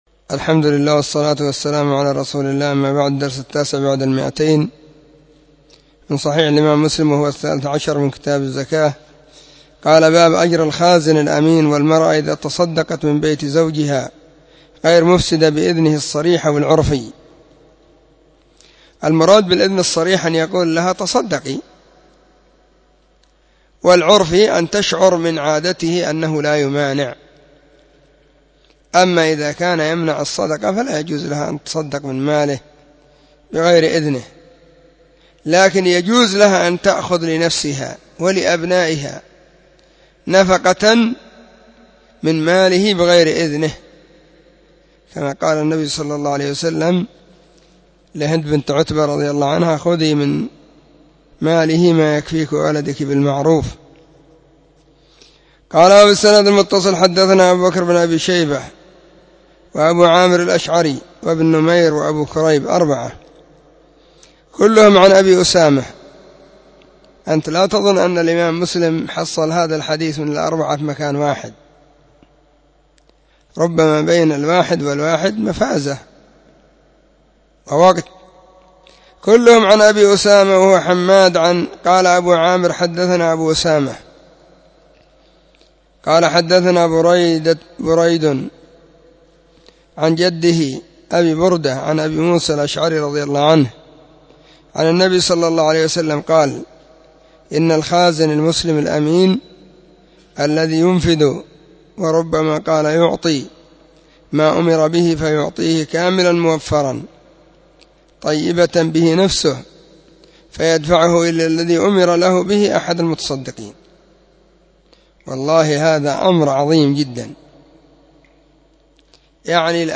📢 مسجد الصحابة – بالغيضة – المهرة، اليمن حرسها الله.
كتاب-الزكاة-الدرس-13.mp3